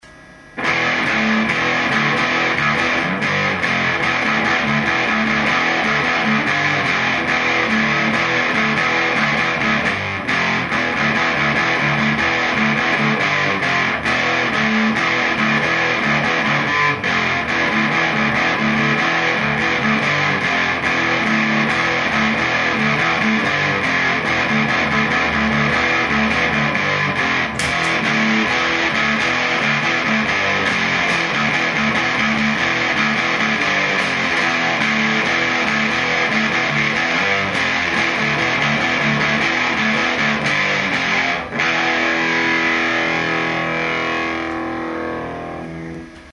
bi-fuzz.mp3